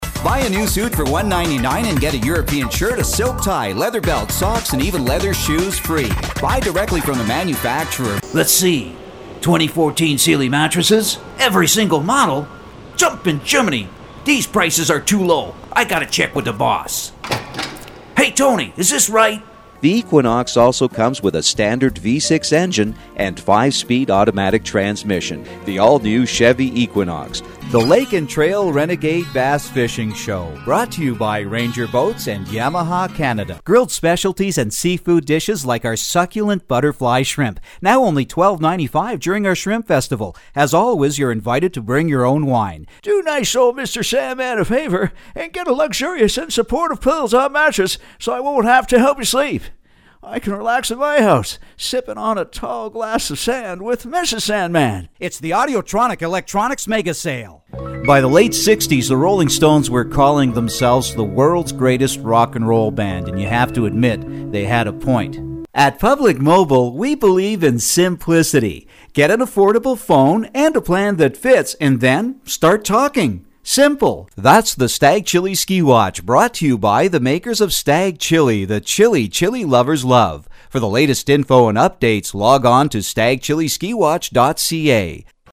Sprecher us-amerikanisch. 25 Jahre Radioarbeit: Nachrichten, Sport etc. -
middle west
Sprechprobe: Werbung (Muttersprache):